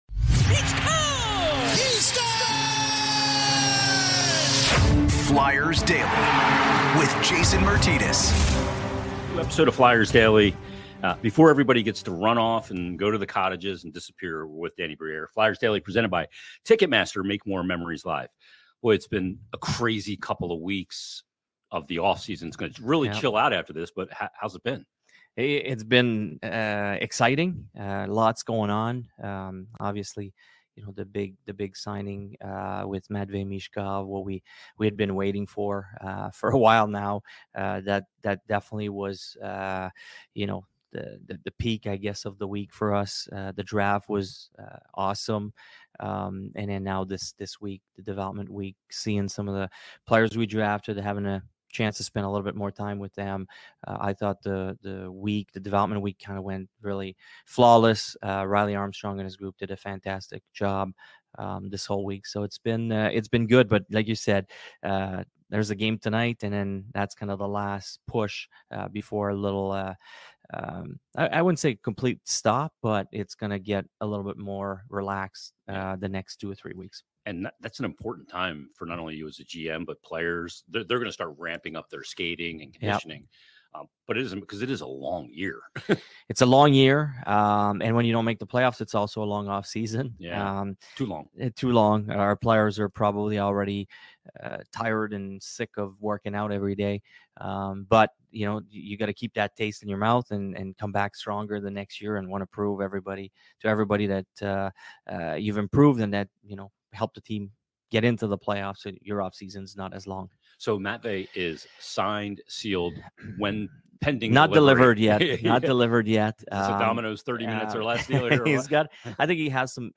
An extended and comprehensive sit down with Flyers General Manager Daniel Brière. We discuss all elements of the offseason including the Draft, Development Camp, Early Arrival of Matvei Michkov, Roster Construction, stage of the rebuild and tons more.